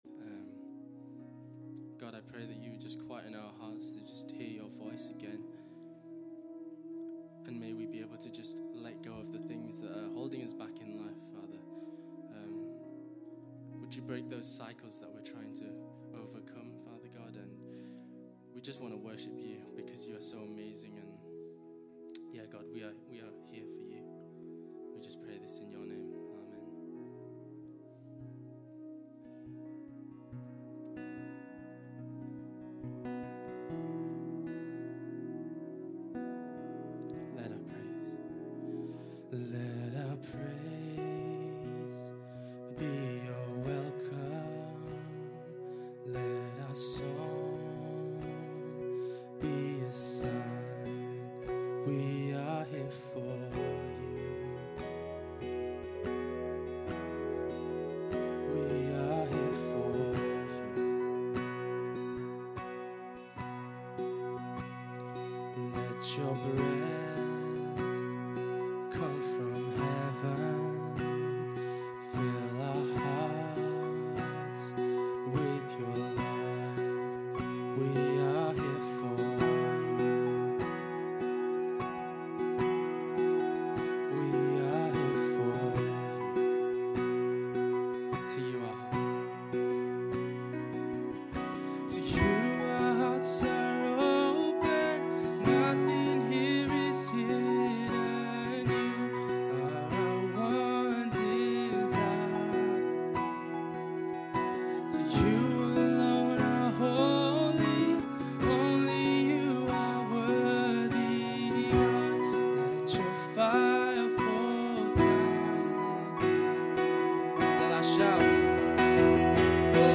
Worship November 6, 2016